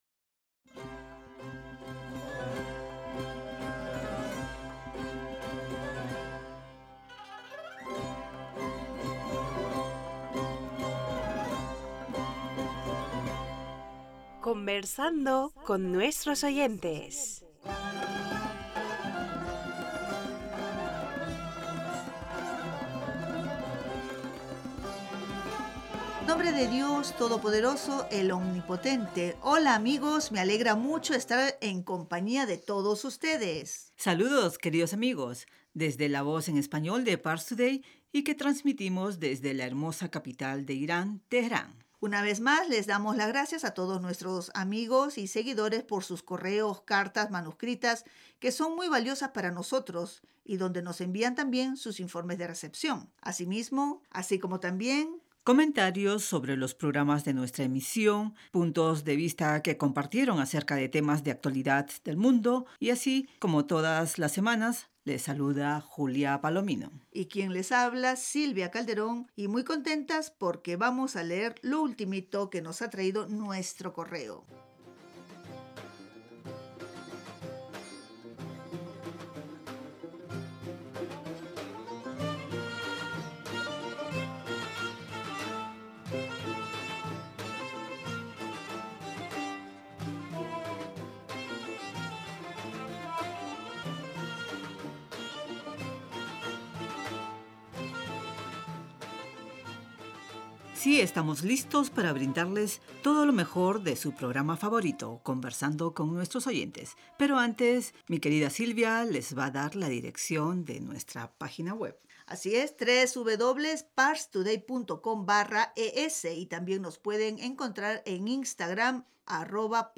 Pars Today-Las entrevistas, leer cartas y correos de los oyentes de la Voz Exterior de la R.I.I. en español.